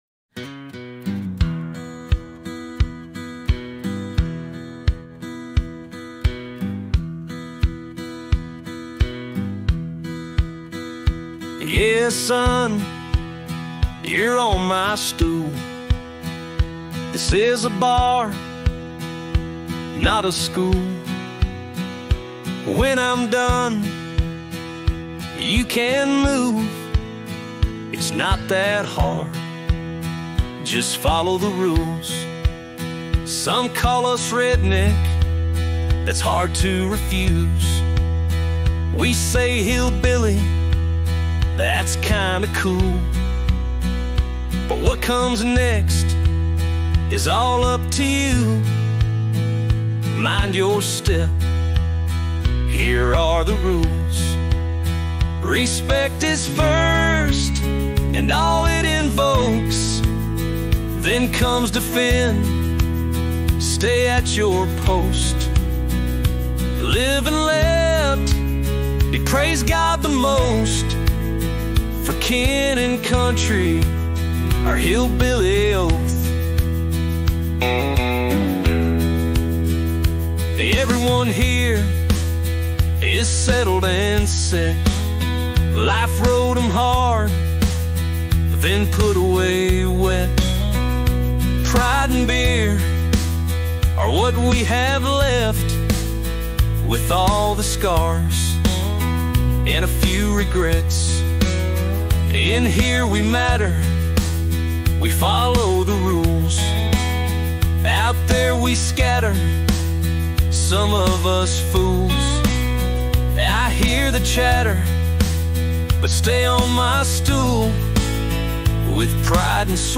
AI(Music)